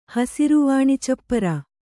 ♪ hasiru vāṇi cappara